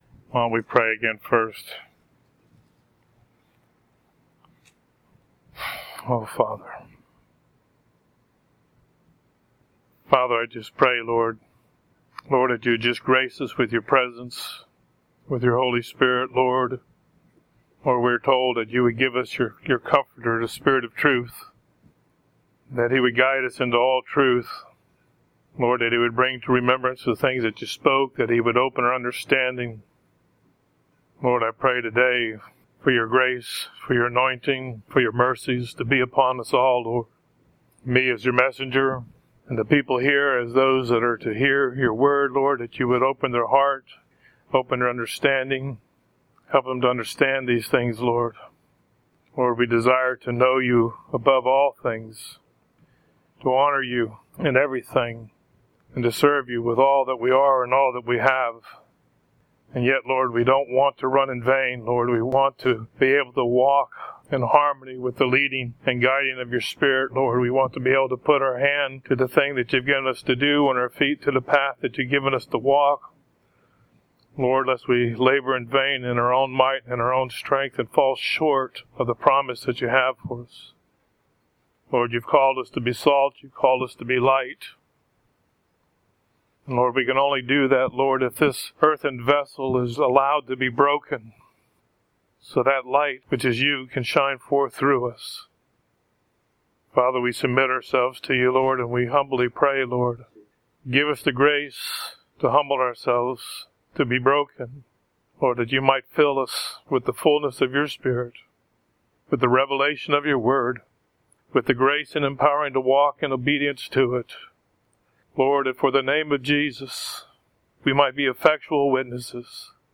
2nd Message share at Covenant Love Church about America – The Eighth Beast
After my first message, I was asked back to speak again in more depth on America in scripture at Covenant Love Fellowship.